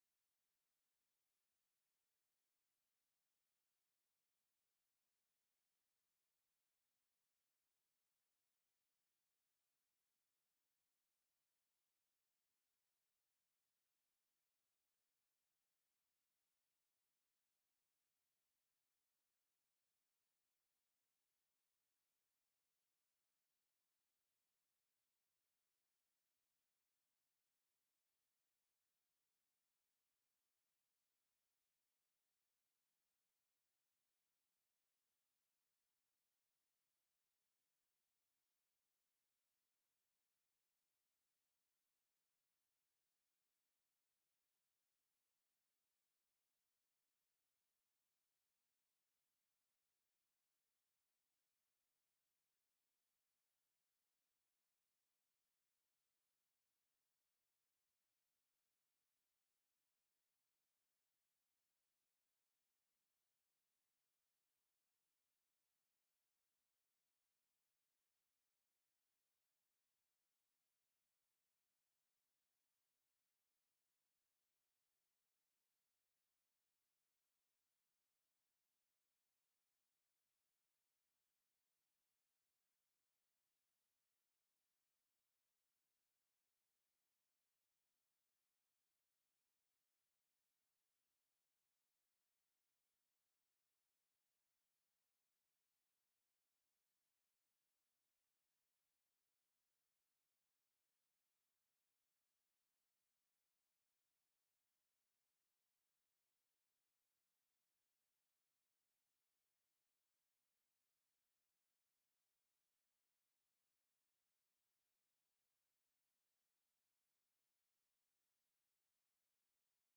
June 9th 2024 Worship and Praise
Praise Worship